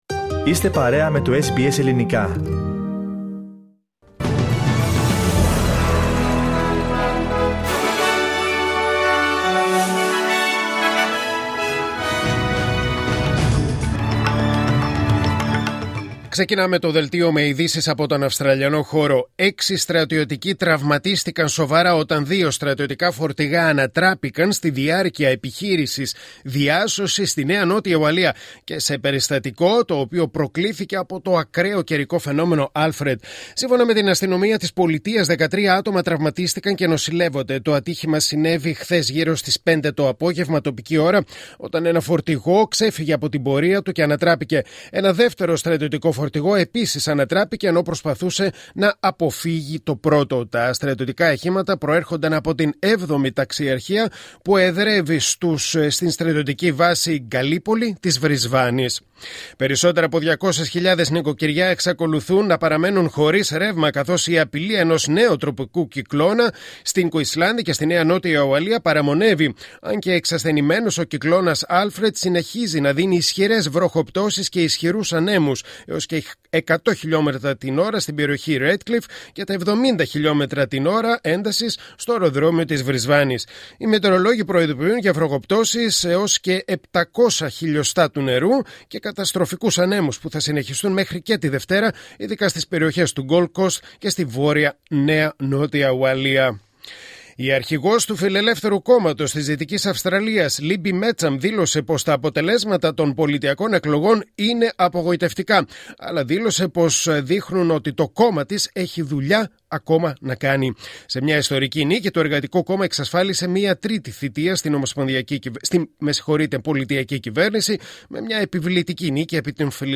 Δελτίο Ειδήσεων Κυριακή 09 Μαρτίου 2025